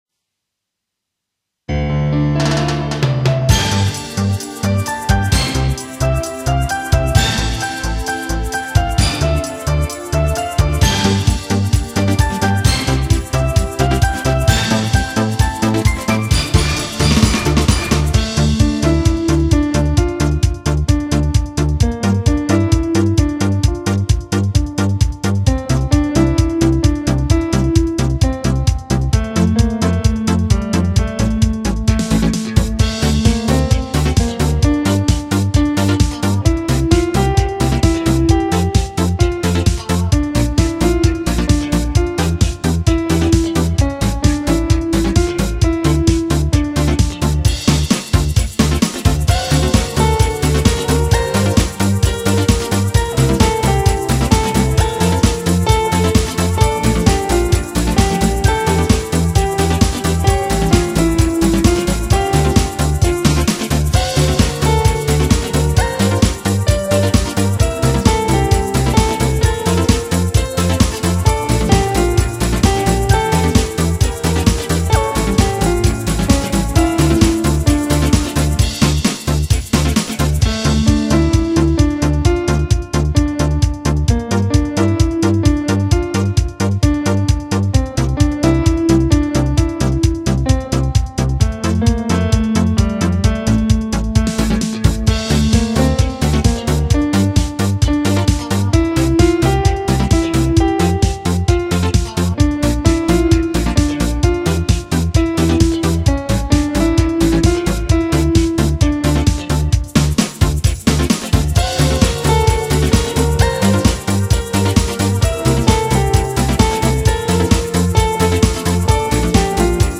Я, як складач мелодій, ритм тут дав би трішки лагідніший.
Пісня у світлих тонах і у щасливому закінченню.